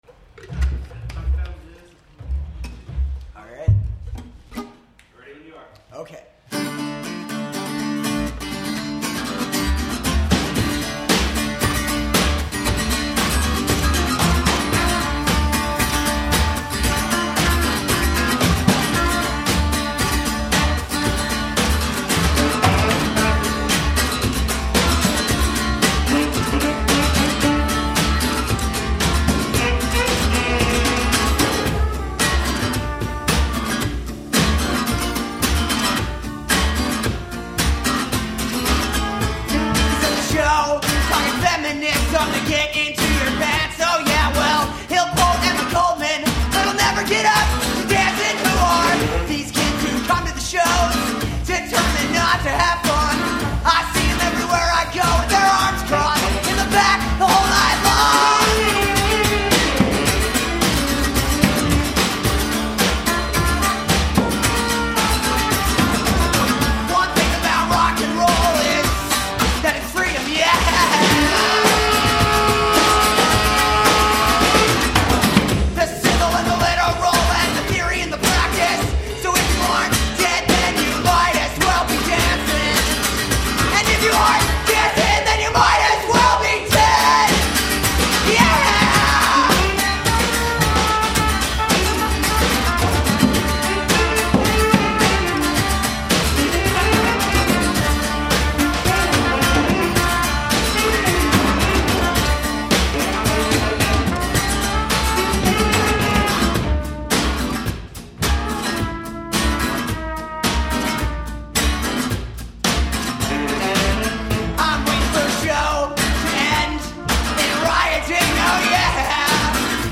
Usually not into folk punk but this is good.